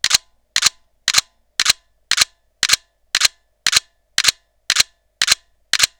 Sorozat fényképezés, sebesség
FX RAW sorozat 14 bit
D3X_continousFX14bit.wav